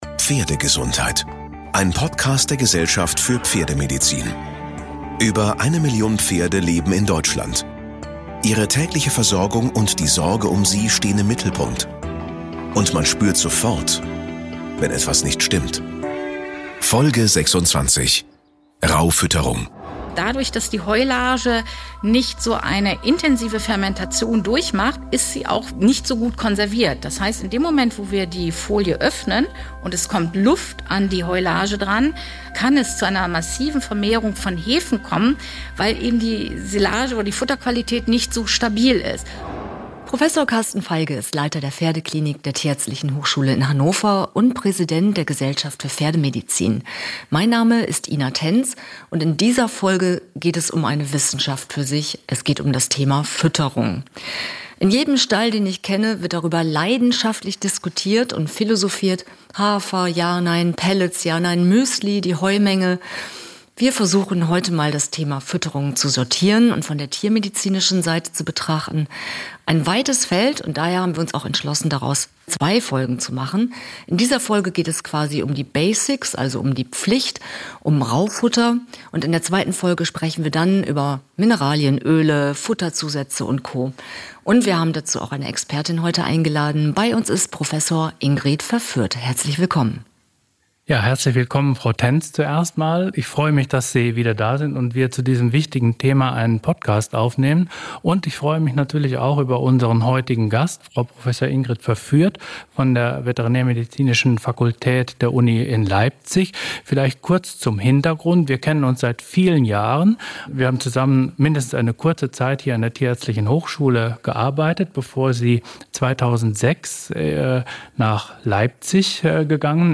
In diesem Podcast beschreibt er – teils mit ExpertenInnen – pro Folge ein Thema zur Pferdegesundheit. Es geht um Erkrankungen, vor allem um deren frühzeitige Erkennung und um die Behandlung. Es geht aber auch um Gesundheitsvorsorge, Fütterung und um Themen rund um Zucht und Haltung.